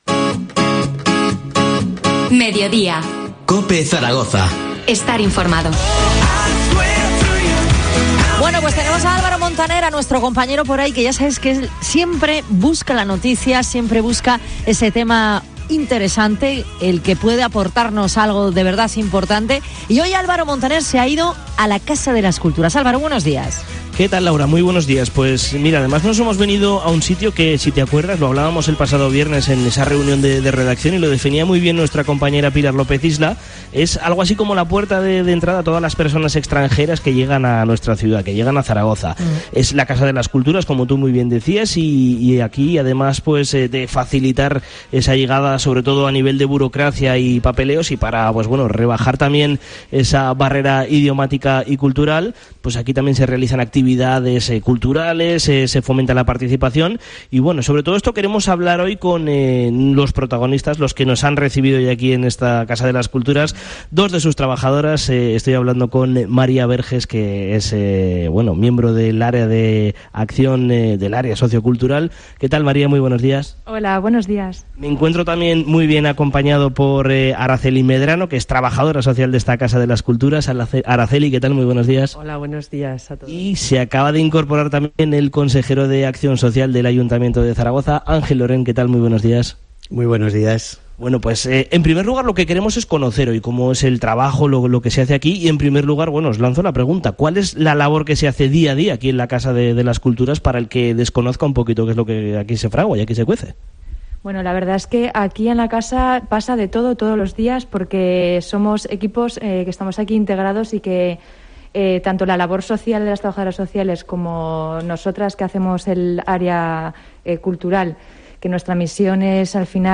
Entrevista
Este lunes en COPE Más Zaragoza hemos podido hablar desde su sede con el Consejero de Acción Social, Ángel Lorén , y con dos de las trabajadoras que componen el equipo profesional de dicha entidad